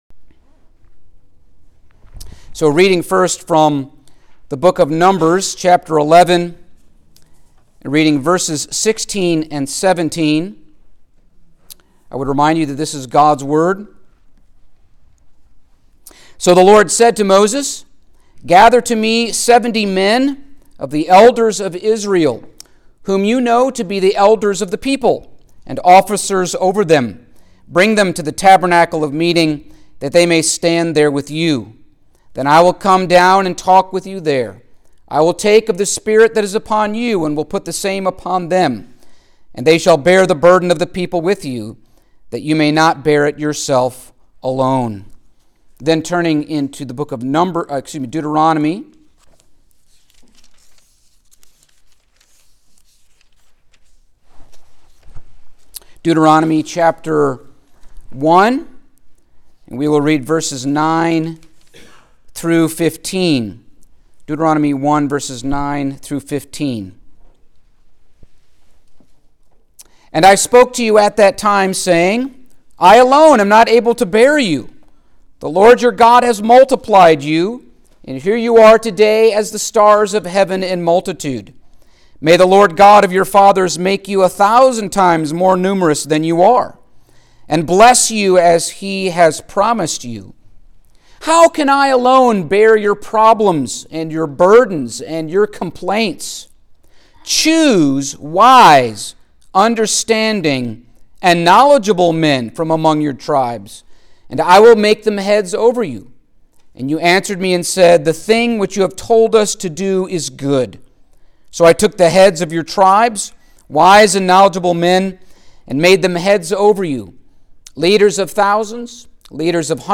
Service Type: Special Service Topics: Eldership , Presbyterian Church Government